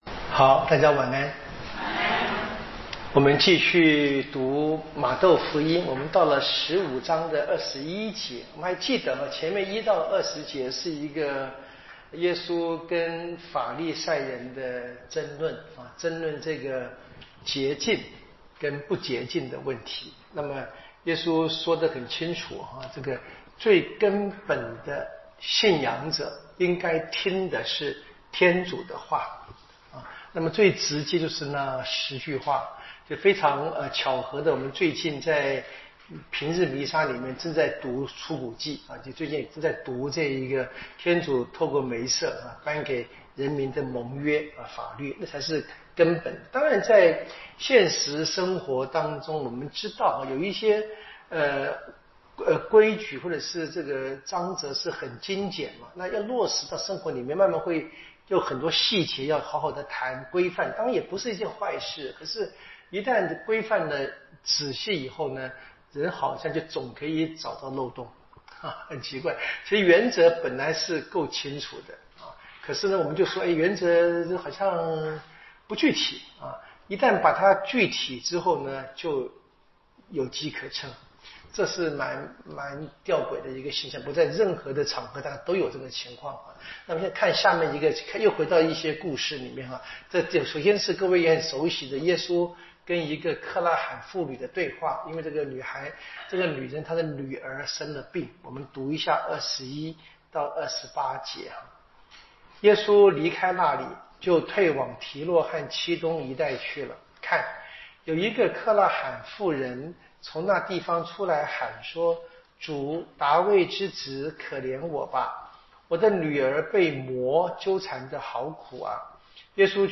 【圣经讲座】